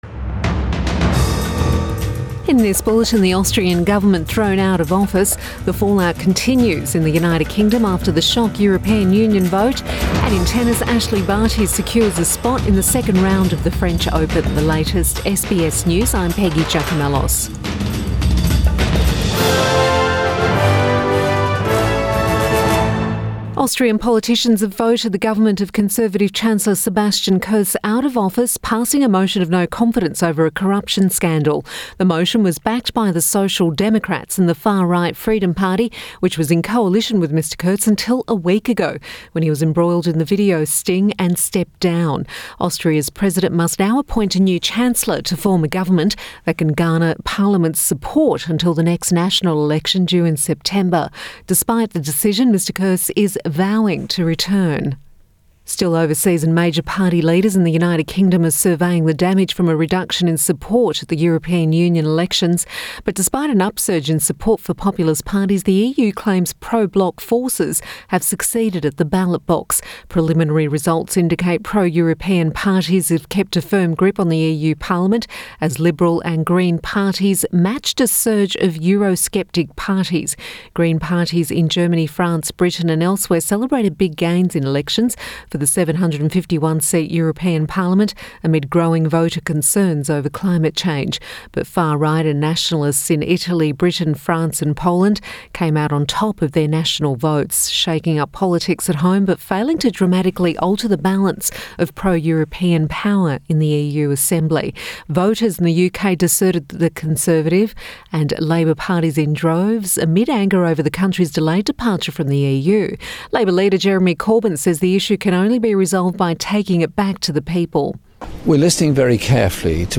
AM bulletin 28 May 2019